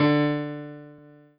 piano-ff-30.wav